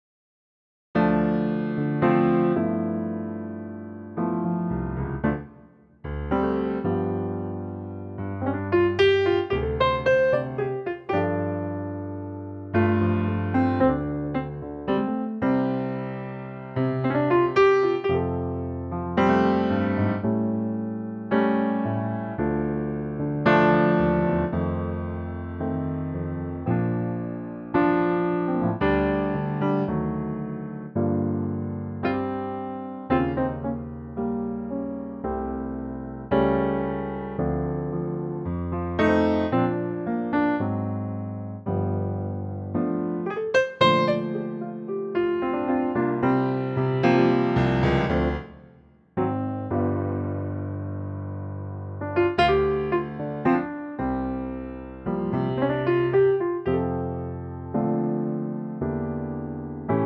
Unique Backing Tracks
key - F - vocal range - C to D